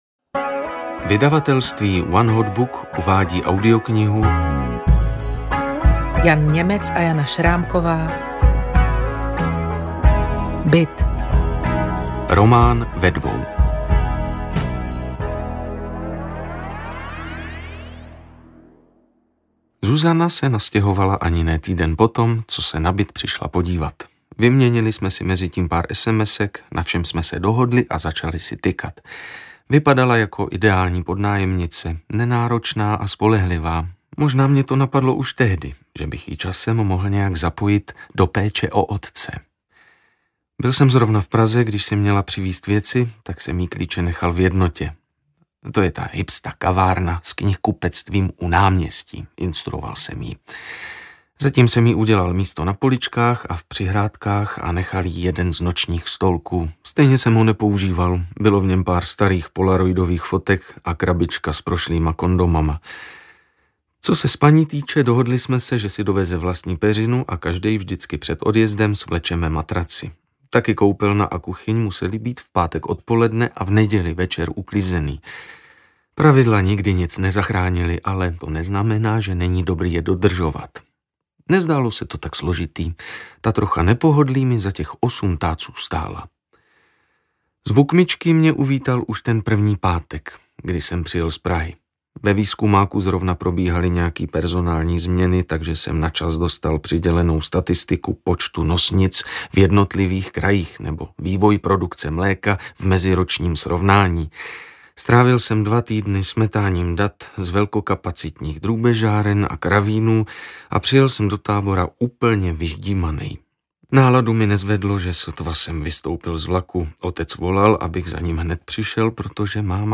Ukázka z knihy
byt-roman-ve-dvou-audiokniha